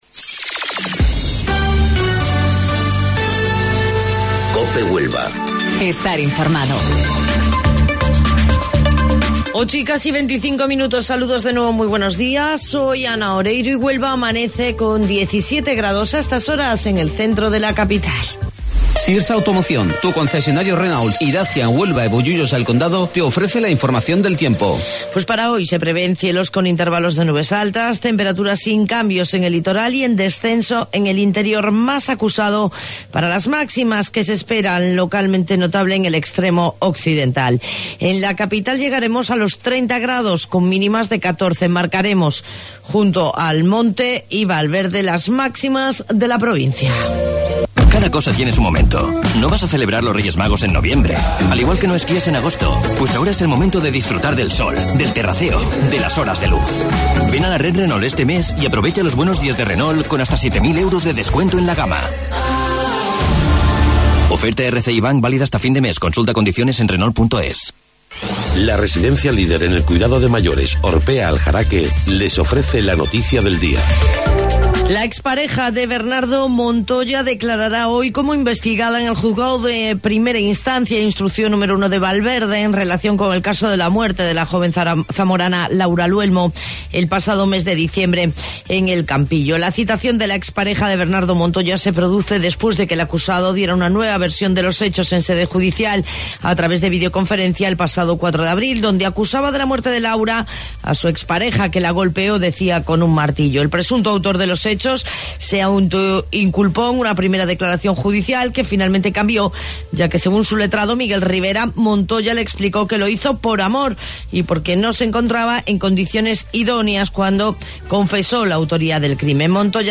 AUDIO: Informativo Local 08:25 del 16 de Mayo